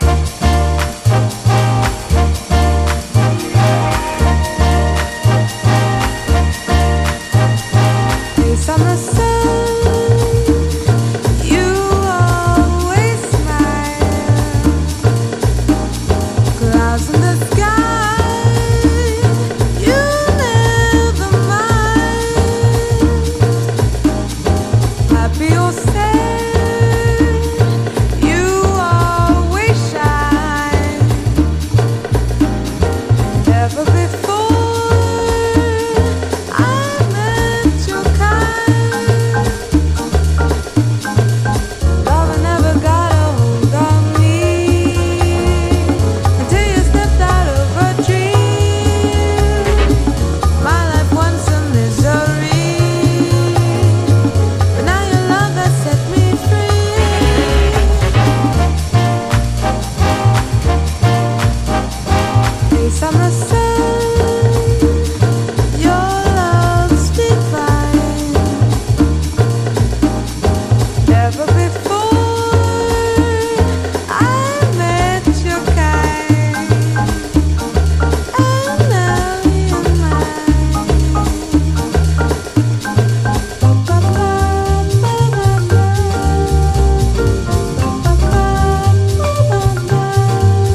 BASS
BONGOS
FLUTE, BRASS [REEDS]
VIBRAPHONE